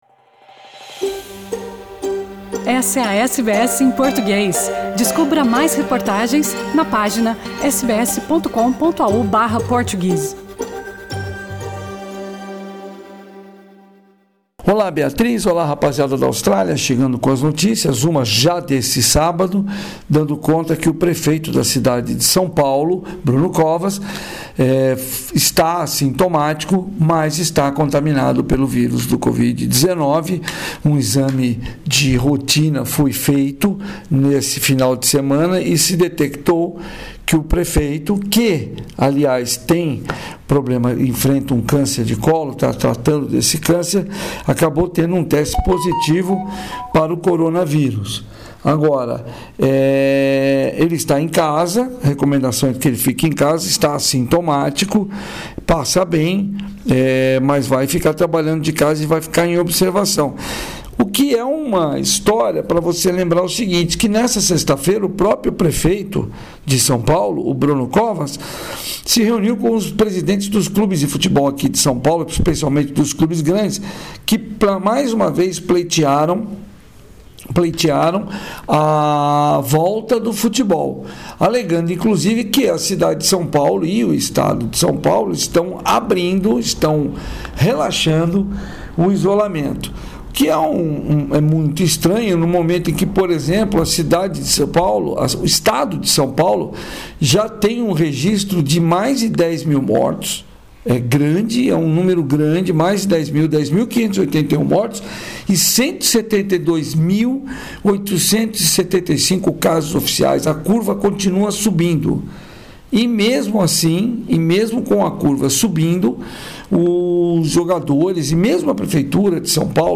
Também neste boletim, Neymar já está em Paris e vai cumprir quarentena antes de voltar a treinar no PSG.